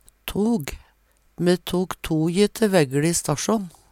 tog - Numedalsmål (en-US)